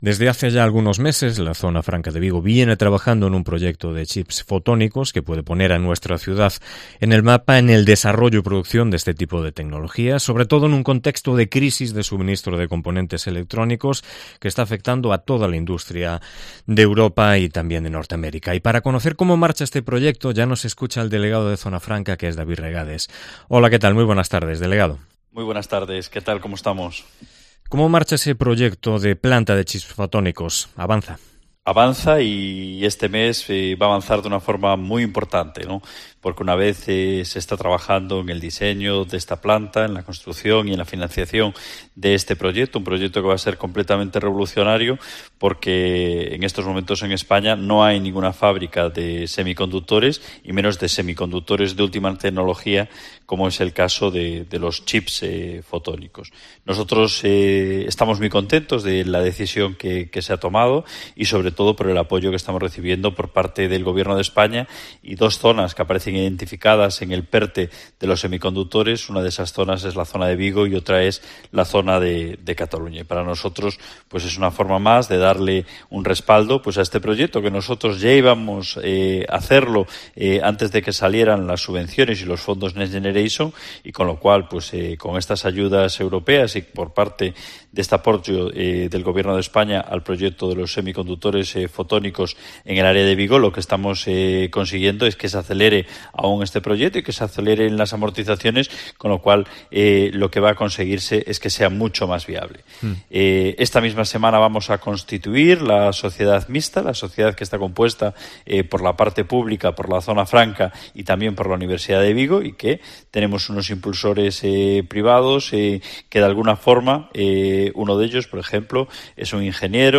AUDIO: Entrevista con el delegado de Zona Franca de Vigo